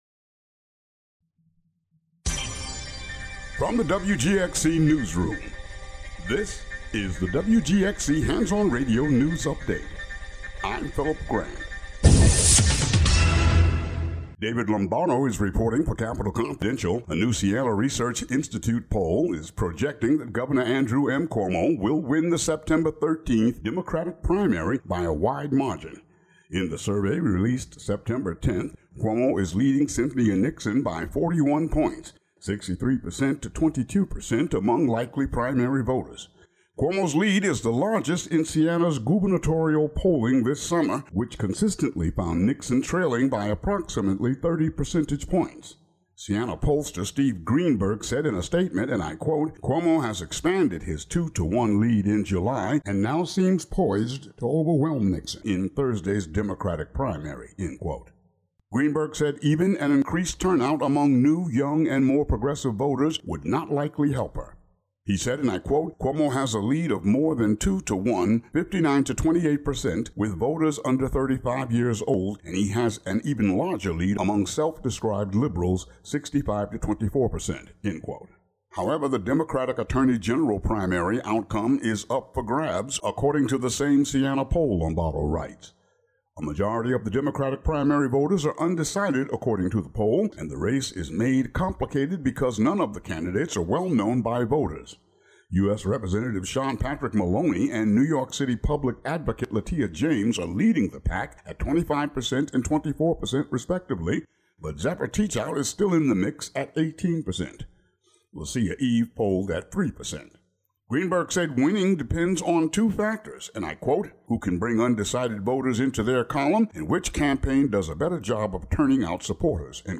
News update for the area.